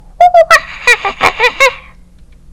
Audio / SE / Cries / AMBIPOM.mp3